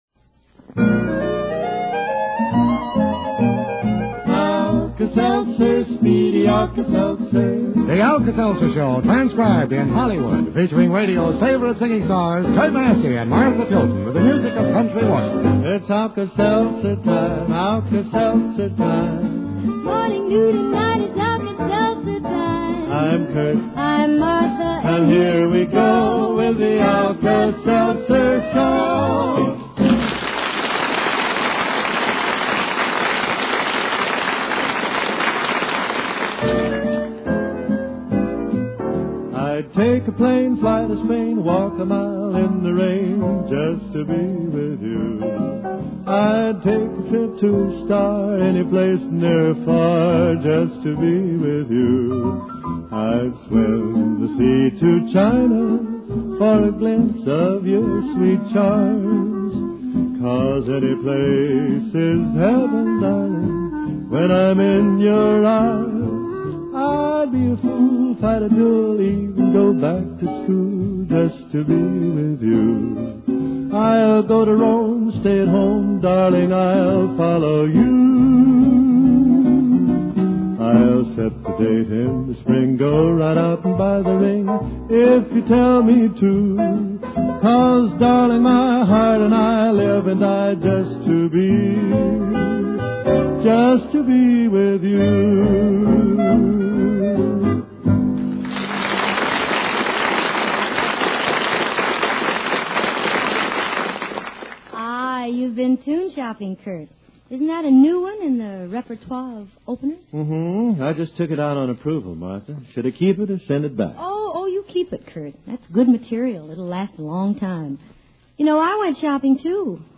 The system cue is added live.